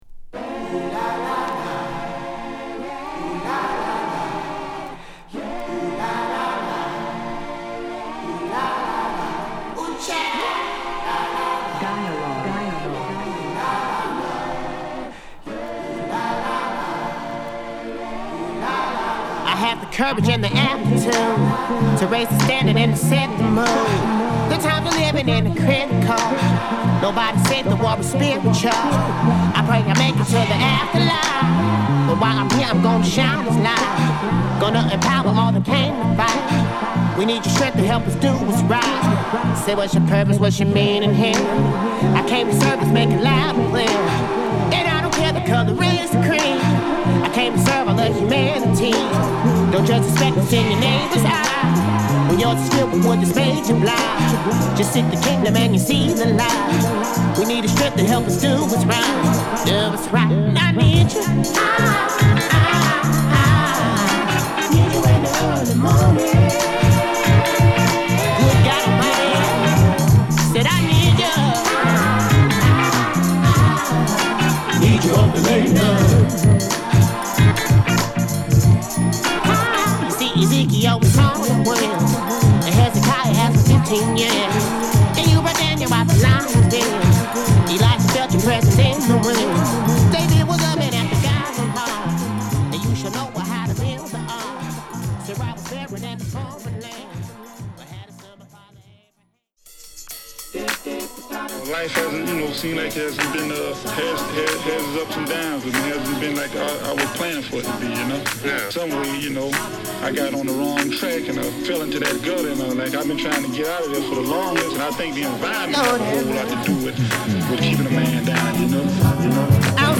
素晴らしいバランス感を持ったソウルフルミュージックを披露！